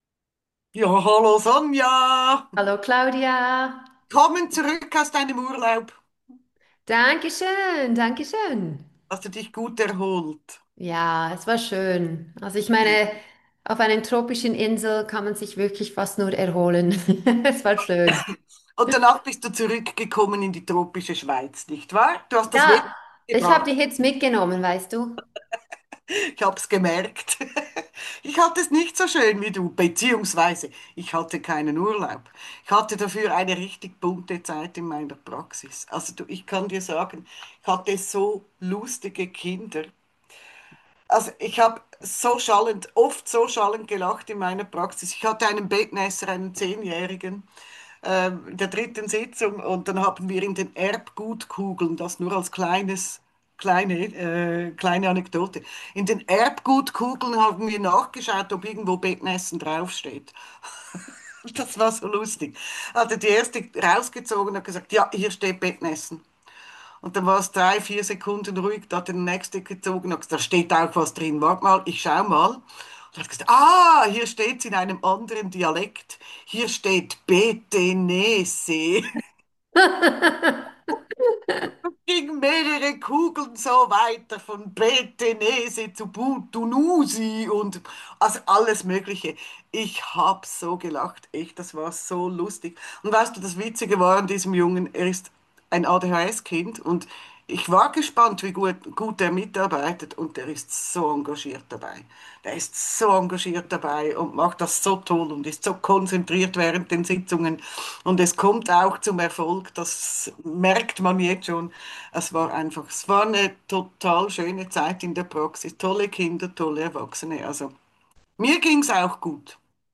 Der spontane Wochentalk